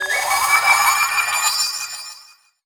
magic_device_transform_02.wav